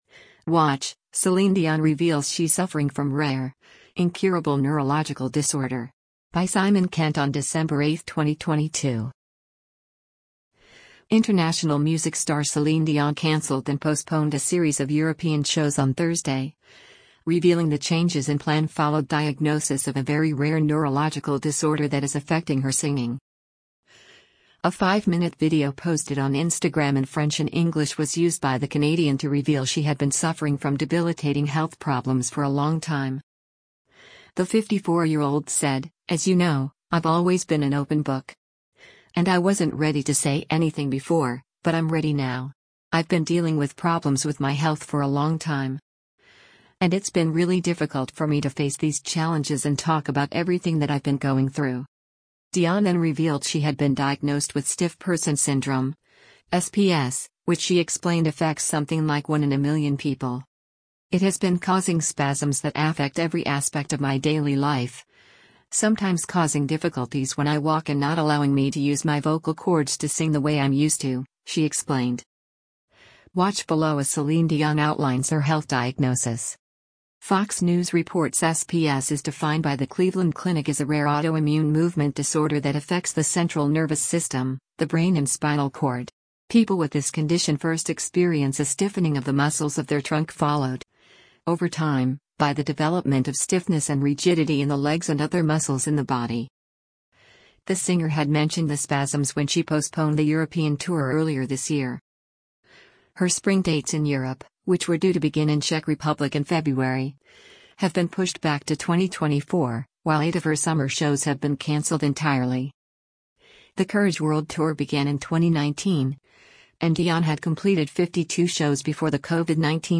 A five-minute video posted on Instagram in French and English was used by the Canadian to reveal she had been suffering from debilitating health problems “for a long time.”